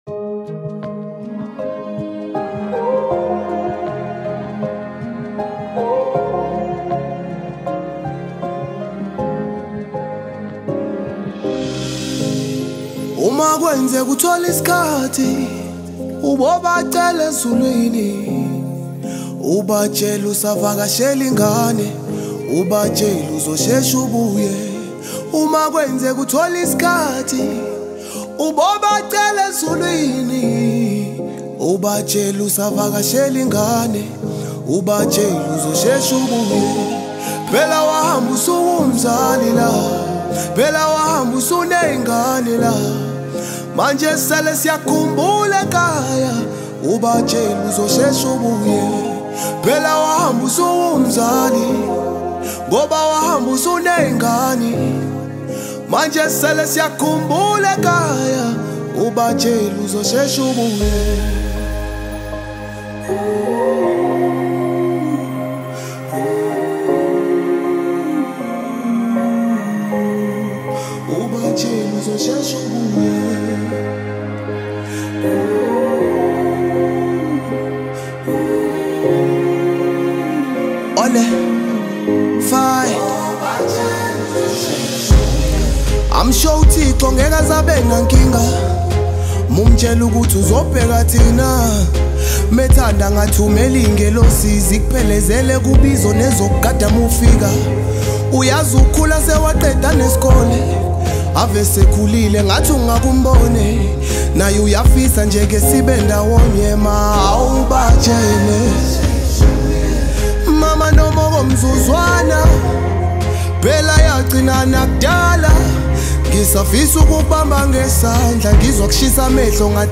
South African rapper
Hip Hop